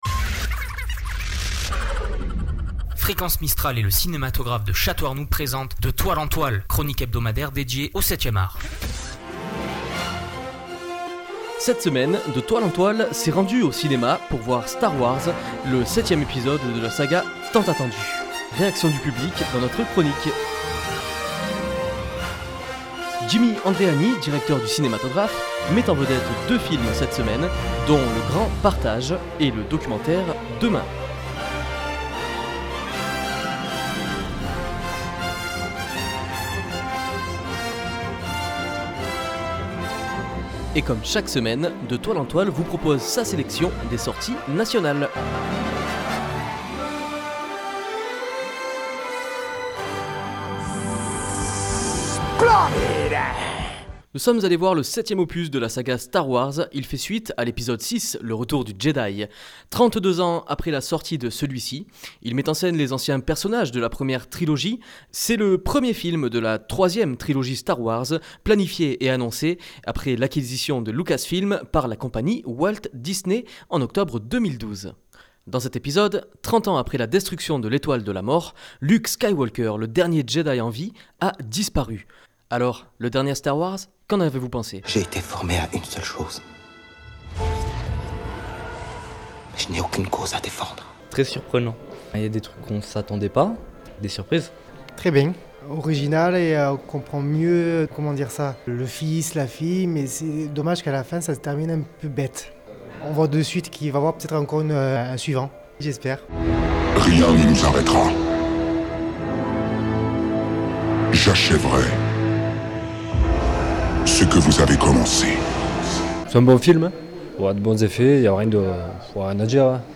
Nous entendrons l'avis du public à la sortie des salles obscures.